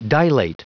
Prononciation du mot dilate en anglais (fichier audio)
Prononciation du mot : dilate